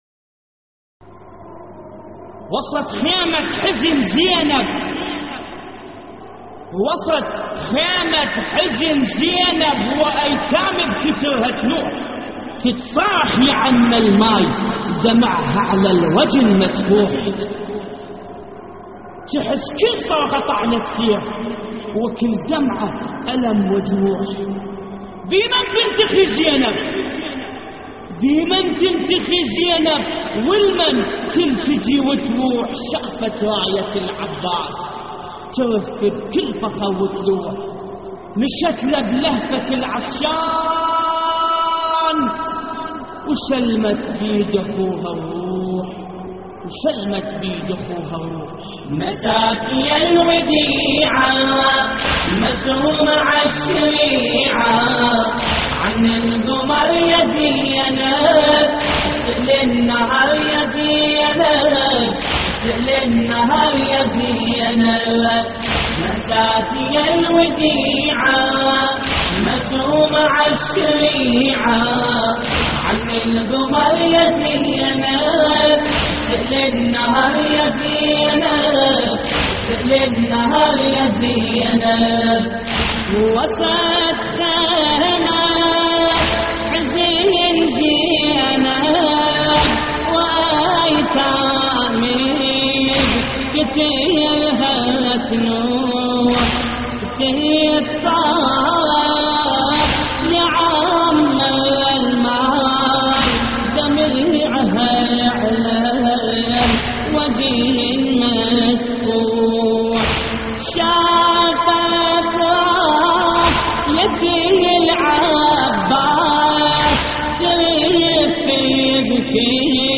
استديو «بنت مسلم» لحفظ الملف في مجلد خاص اضغط بالزر الأيمن هنا ثم اختر